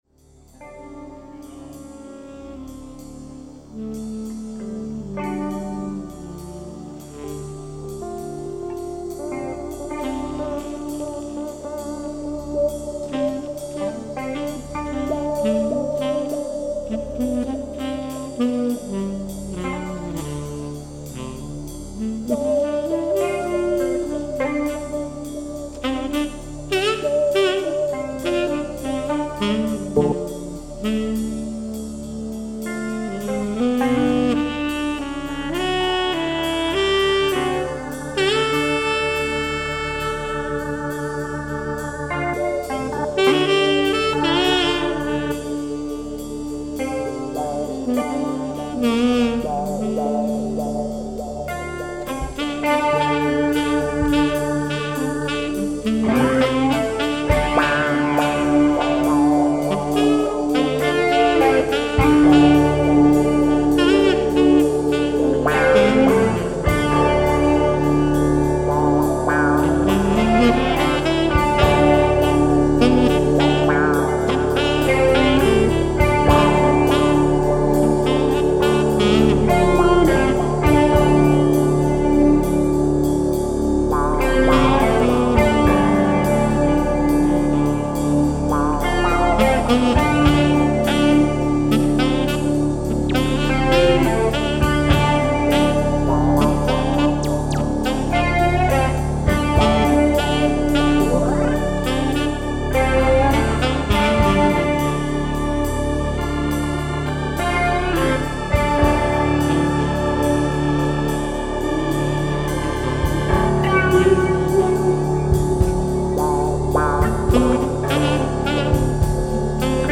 Royalty Free Music
Funk, Soul, Jazz & Electro.